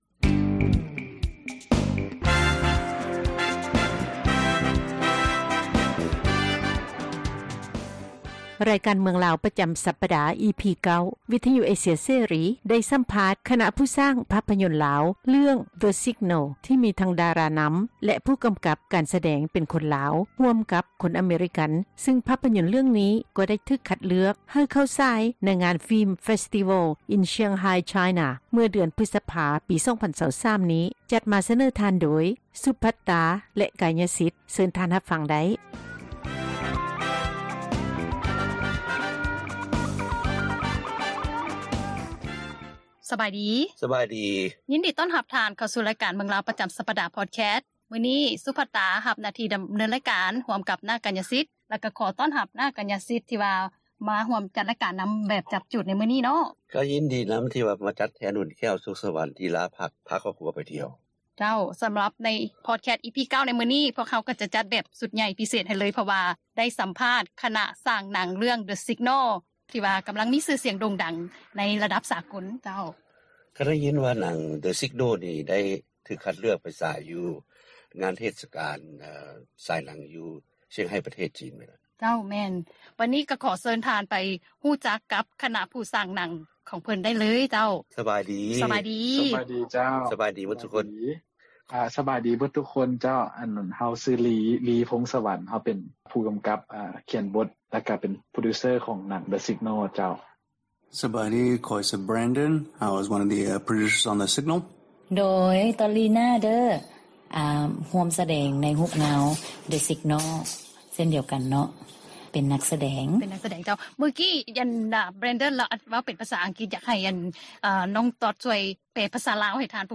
ສໍາພາດ ຄະນະຜູ້ສ້າງພາບພະຍົນລາວ ເລື່ອງ “The Signal”